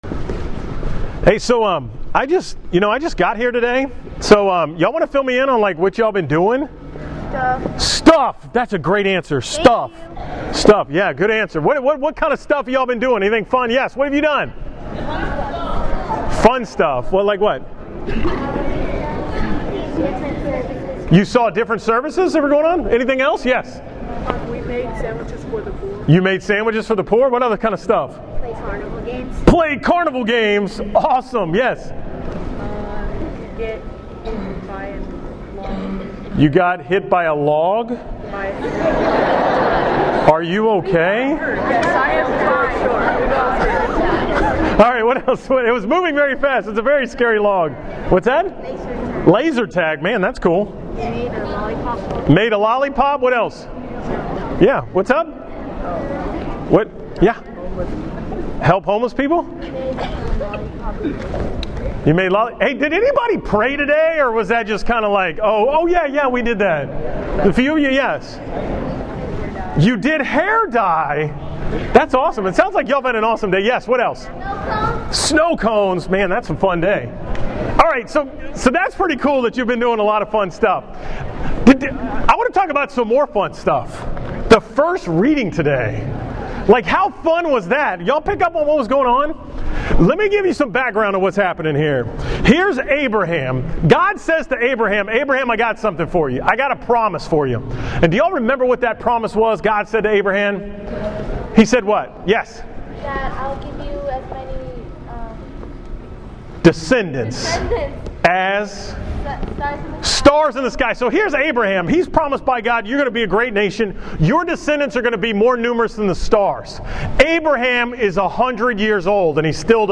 From St. Simon and Jude during the Archdiocesan Middle School event on February 24, 2018